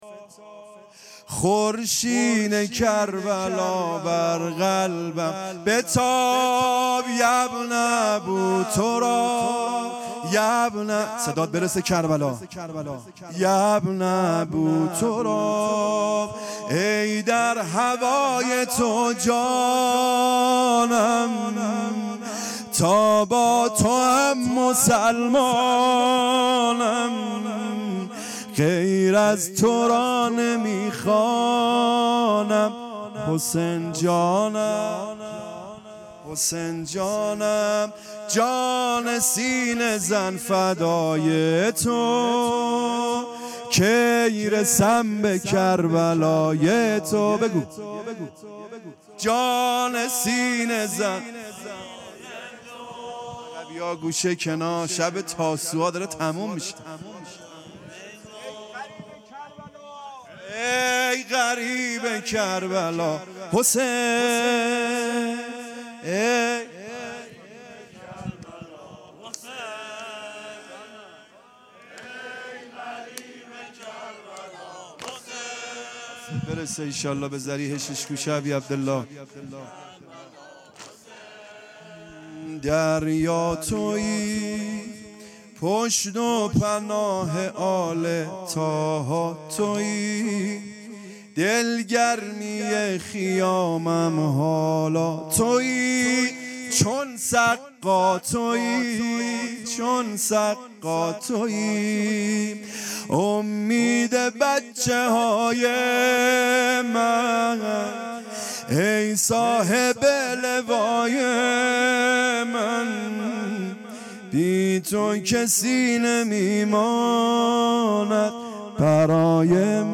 مراسم محرم. ۱۴۰۱ در حرم شهدای گمنام شهرک شهید محلاتی